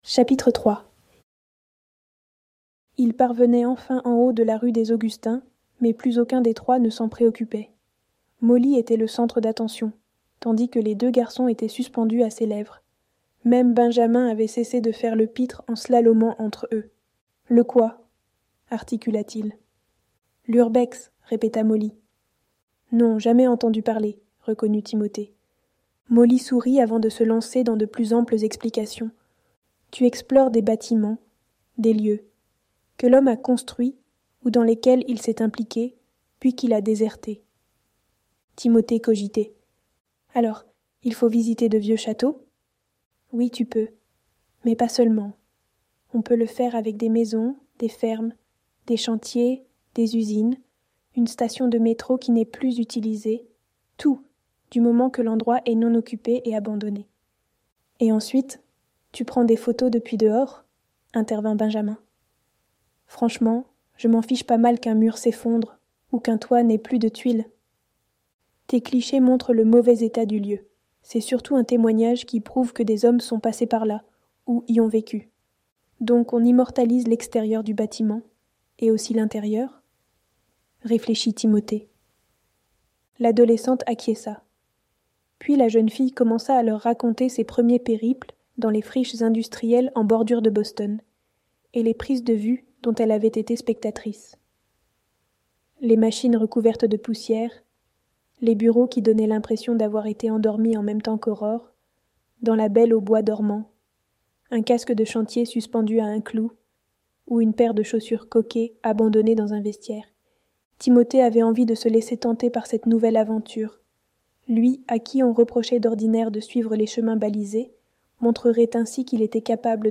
Ce livre audio a été enregistré en utilisant une synthèse vocale.